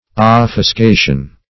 Search Result for " offuscation" : The Collaborative International Dictionary of English v.0.48: Offuscate \Of*fus"cate\, Offuscation \Of`fus*ca`tion\ See Obfuscate , Obfuscation .